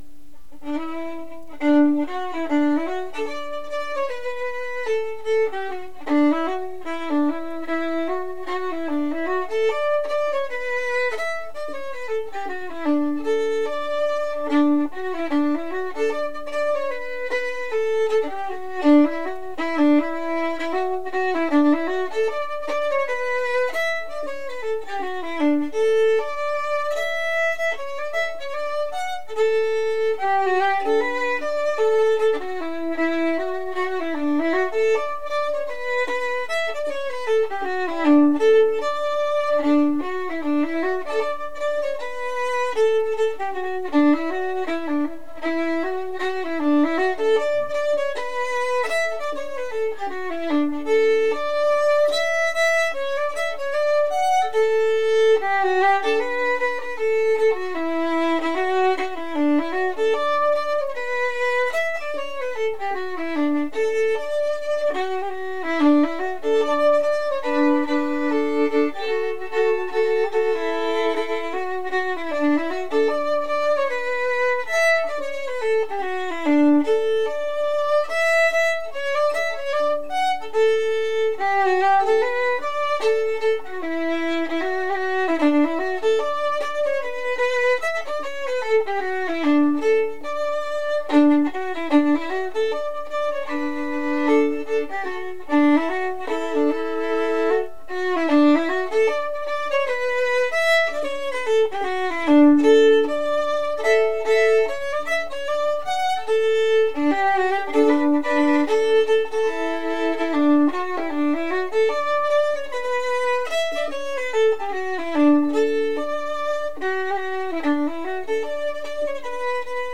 Each link connects to a Morris Dance tune, to play for practice ...
. . . Border Morris Dances: